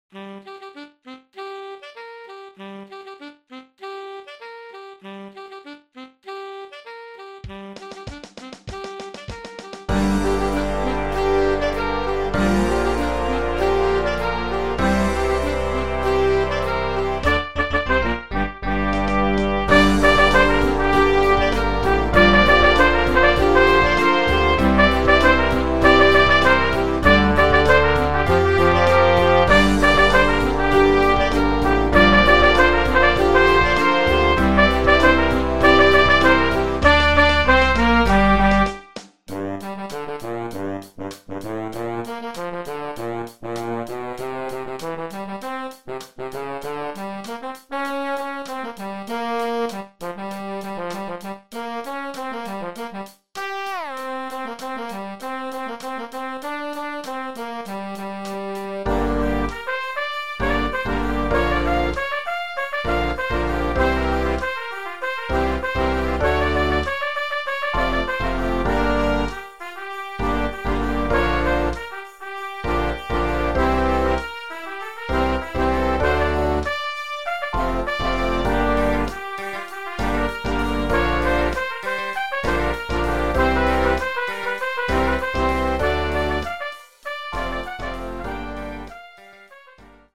Jazz Band
big band arrangement
Instrumentation is 5 saxes, 6 brass, 4 rhythm.